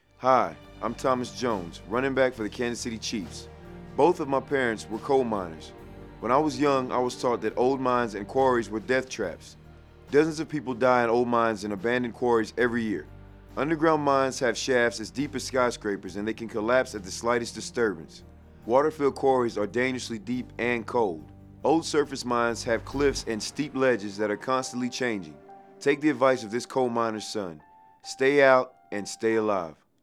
Thomas Jones of the Kansas City Chiefs prepared PSAs for the US Department of Labor